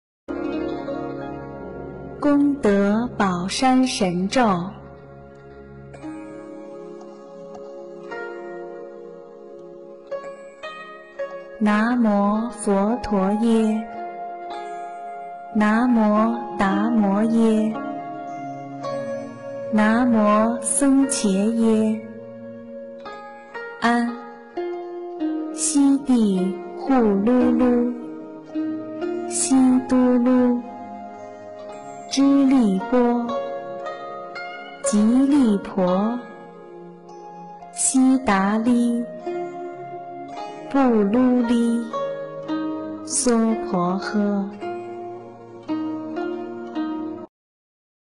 《功德宝山神咒》英文·最美大字拼音经文教念